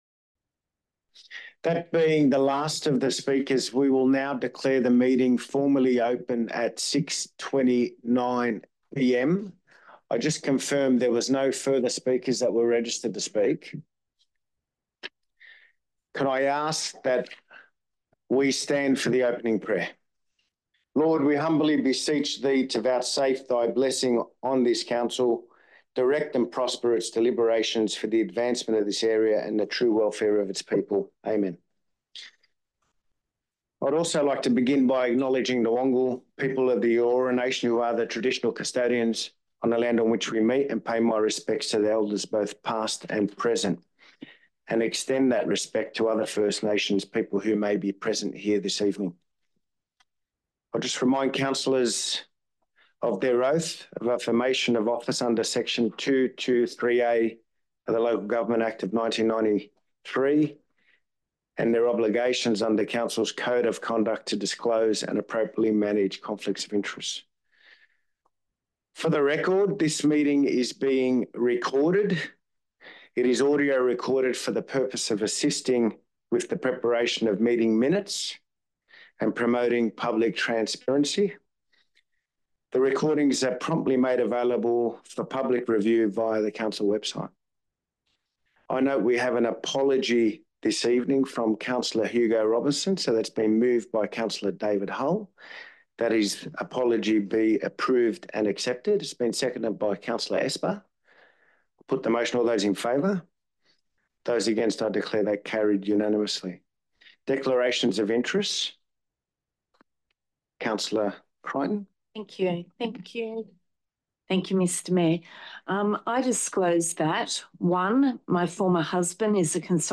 Notice is hereby given that a meeting of the Council of Burwood will be held in the Conference Room, 2 Conder Street, Burwood on Tuesday 21 May 2024 at 6.00 pm to consider the matters contained in the attached Agenda.
edited-version-without-public-forum.mp3